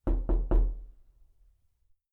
knock-1.mp3